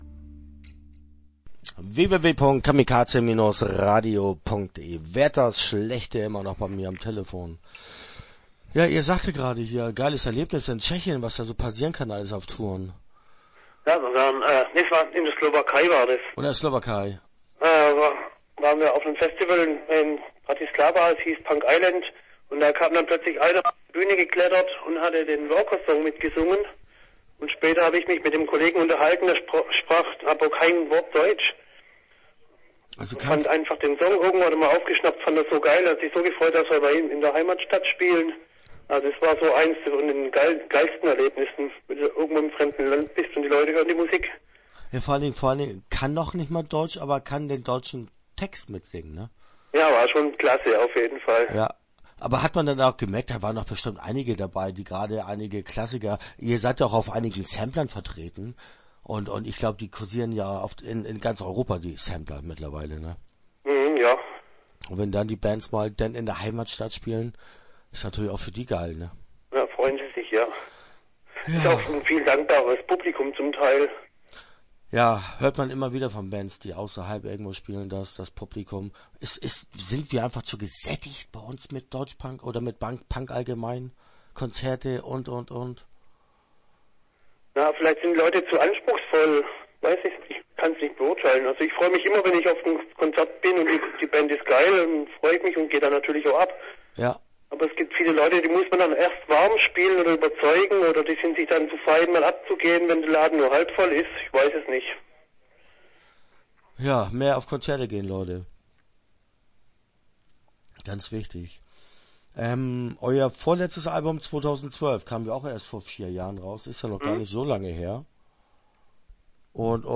Start » Interviews » Wärters schlEchte